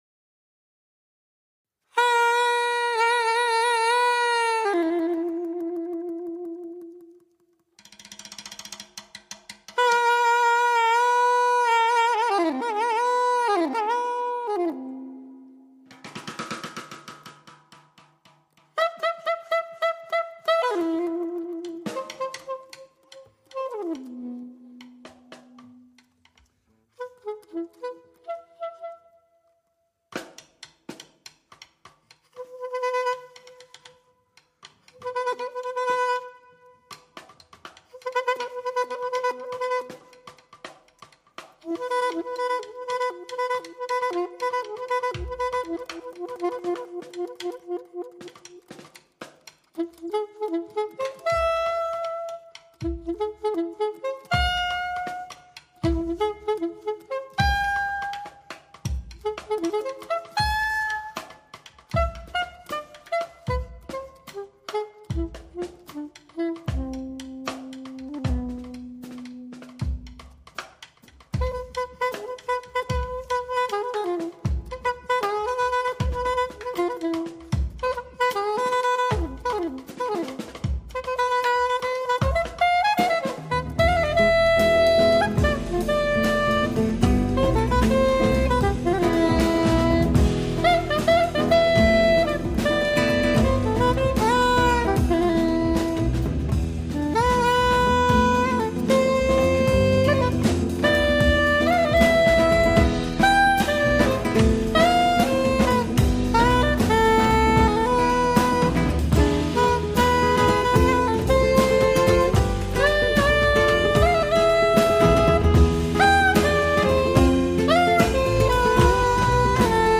sax,bcl
guitars
bass
drums